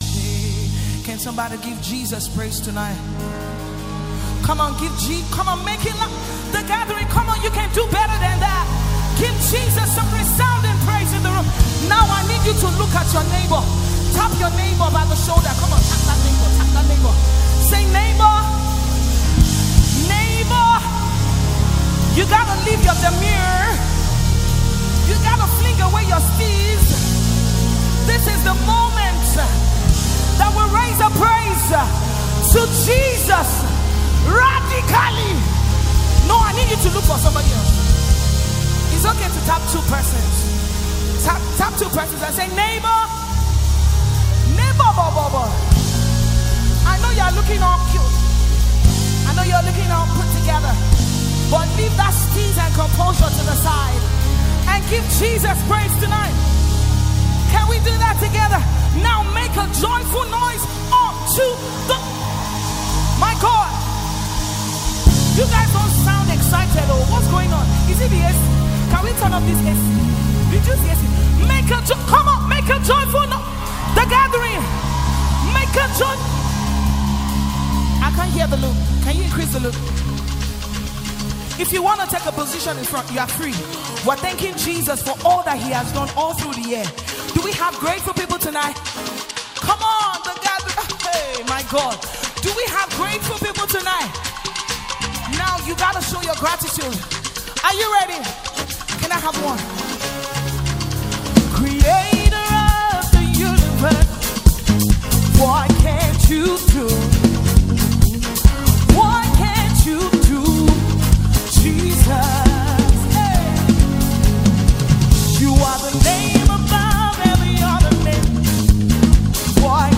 a powerful worship song to uplift and inspire you.